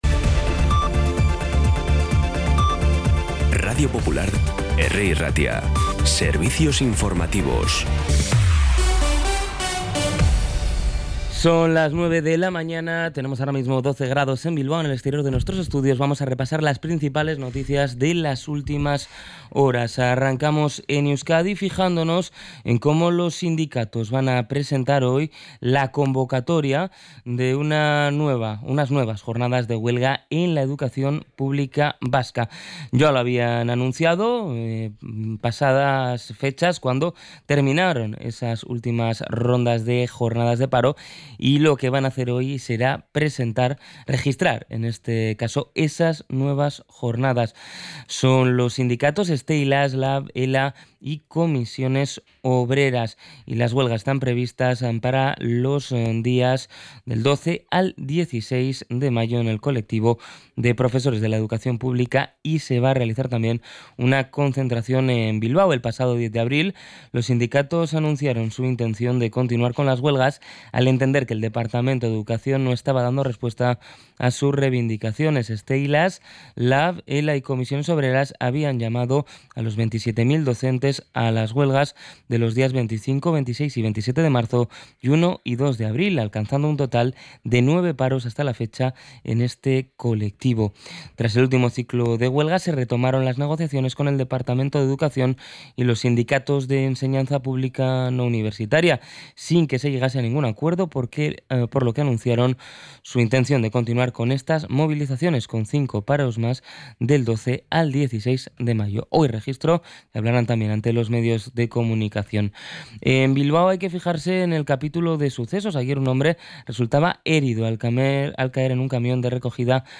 Las noticias de Bilbao y Bizkaia del 5 de mayo las 9
Los titulares actualizados con las voces del día.